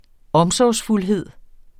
Udtale [ ˈʌmsɒwsfulˌheðˀ ]